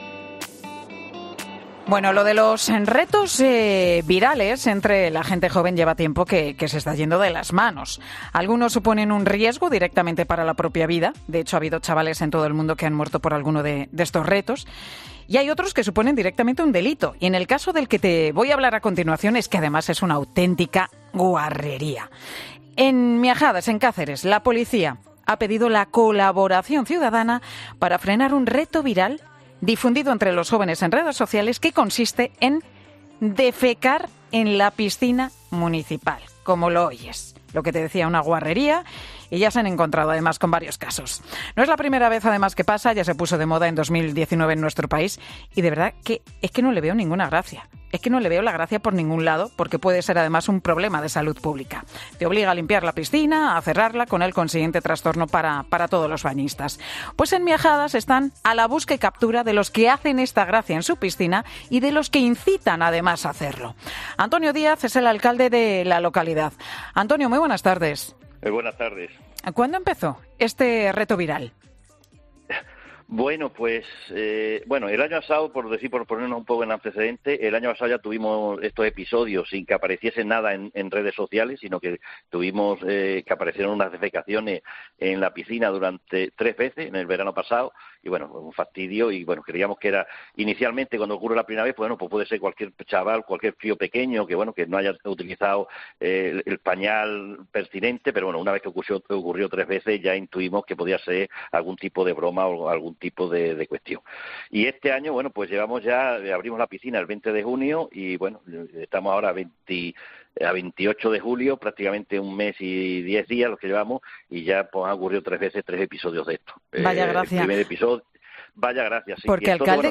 Antonio Díaz, alcalde de Miajadas, denuncia en Mediodía COPE lo que está ocurriendo en la piscina municipal